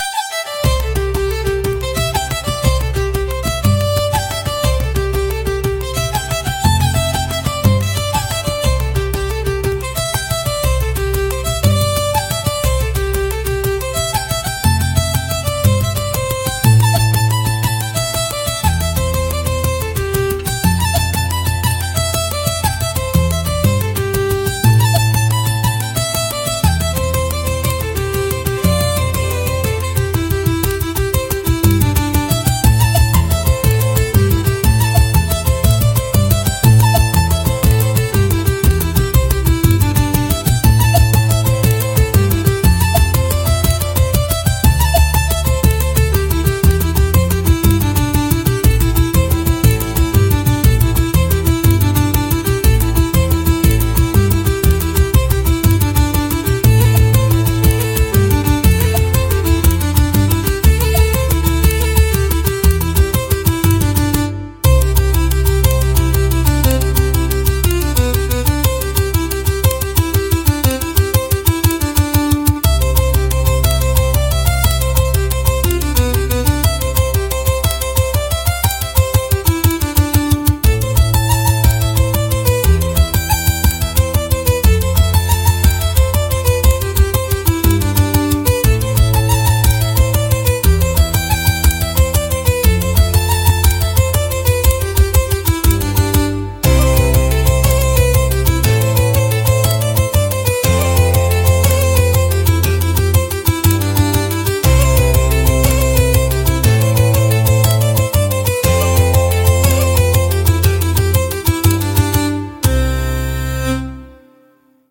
神秘的で豊かな民族色があり、物語性や異国情緒を演出するシーンに効果的なジャンルです。